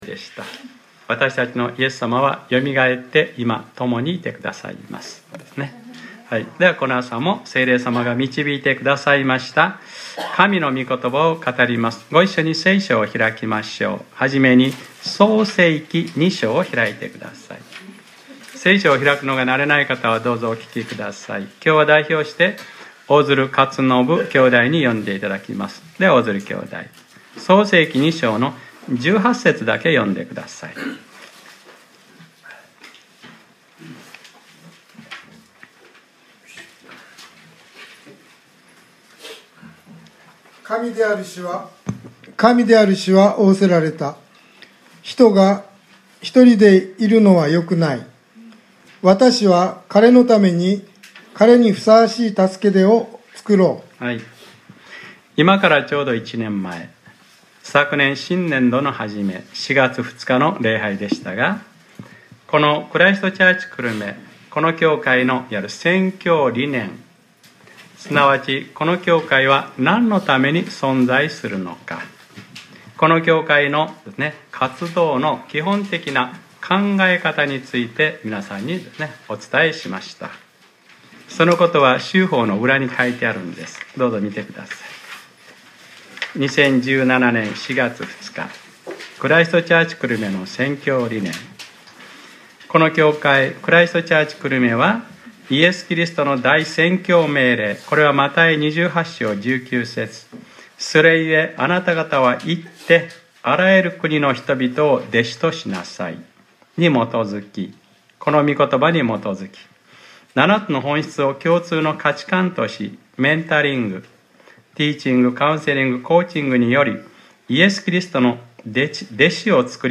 2018年04月08日（日）礼拝説教『メンタリング』